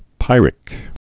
(pīrĭk, pĭrĭk)